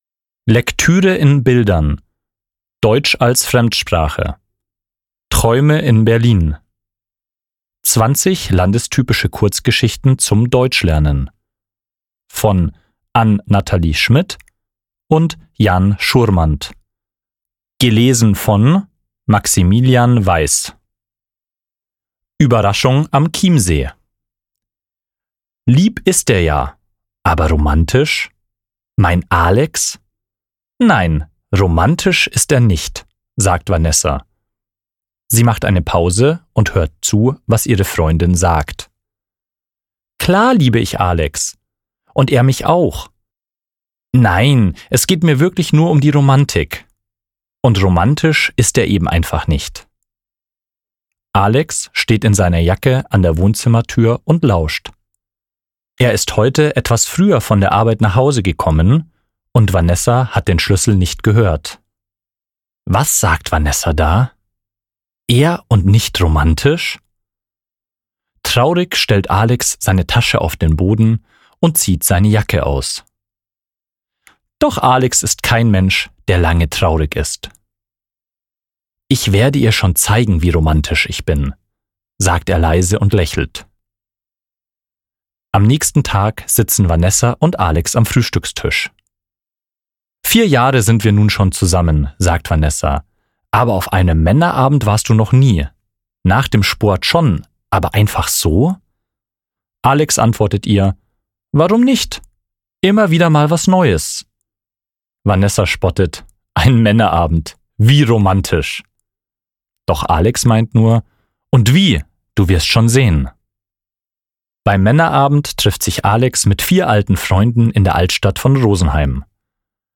PONS Hörbuch Deutsch als Fremdsprache: Träume in Berlin